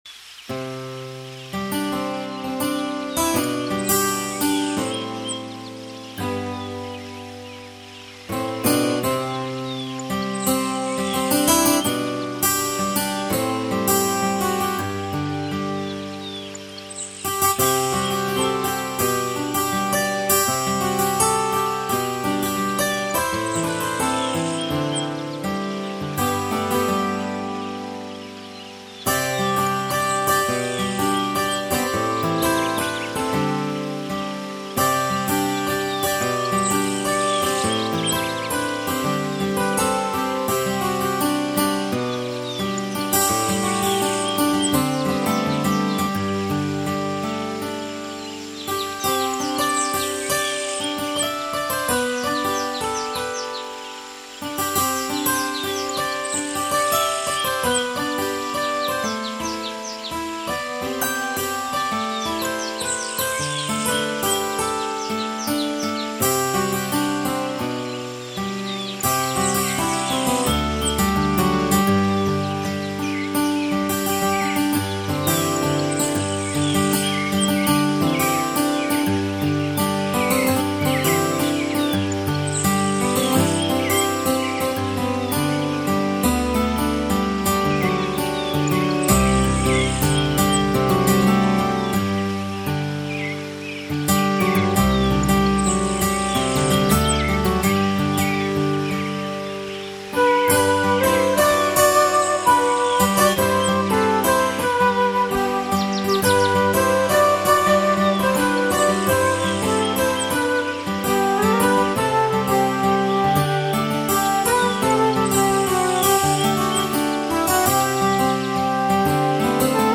Работа с различными компрессорами инструментов и доп. звуками. Более-менее похоже на музыку в раю, какая она там на самом деле, не скажет никто ;)!